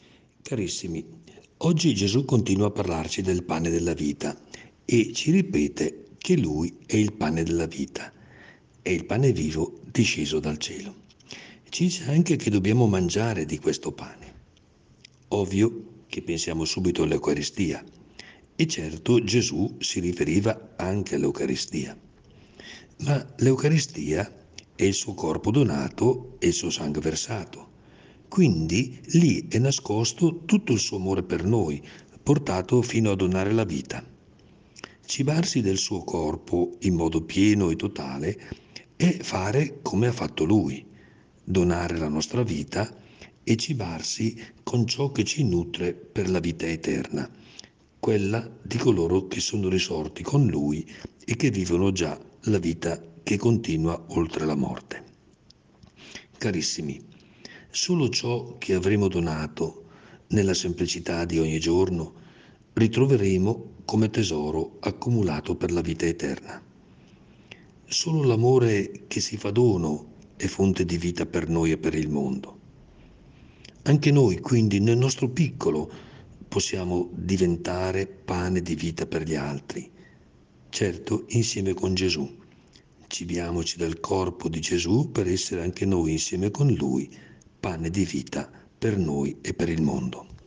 Il Vescovo commenta la Parola di Dio per trarne ispirazione per la giornata.